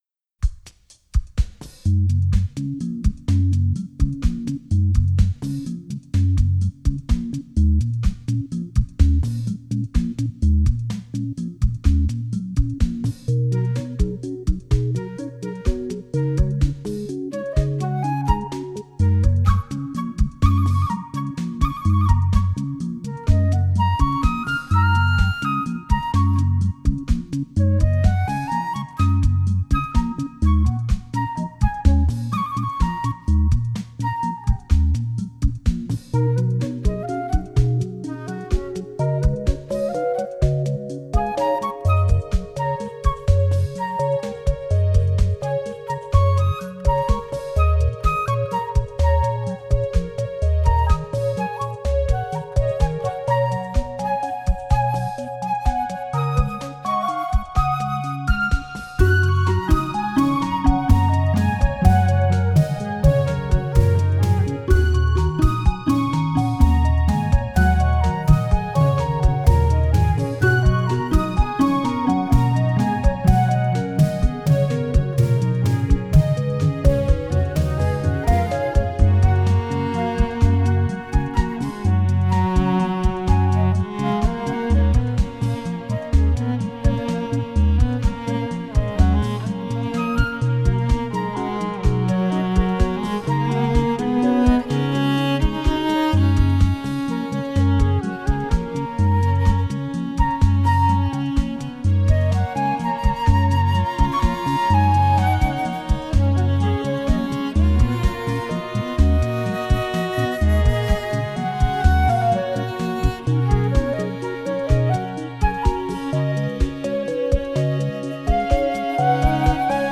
Нью эйдж Музыка релакс New age